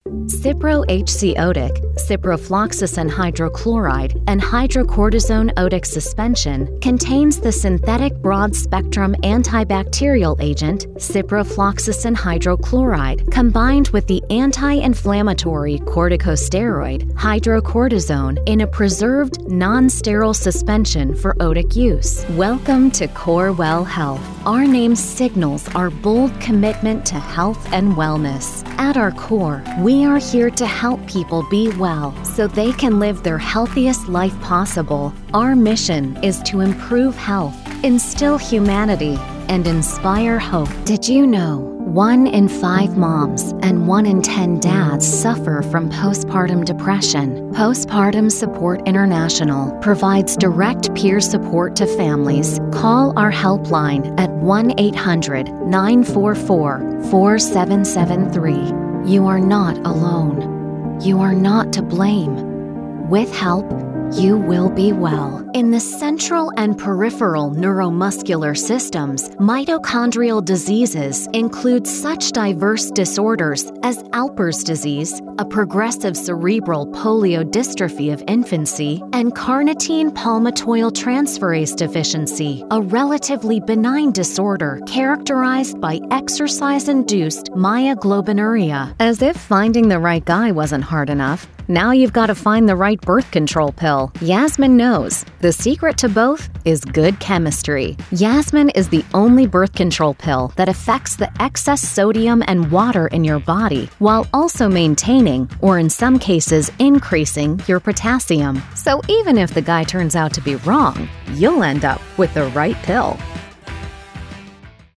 new york : voiceover : commercial : women